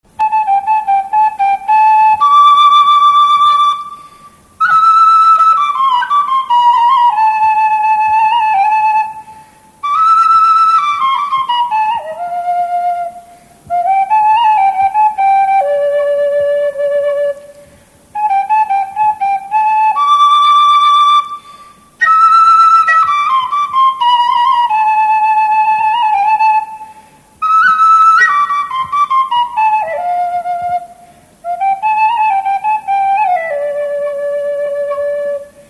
Alföld - Jász-Nagykun-Szolnok vm. - Dévaványa
furulya
Stílus: 1.2. Ereszkedő pásztordalok
Szótagszám: 8.8.8.8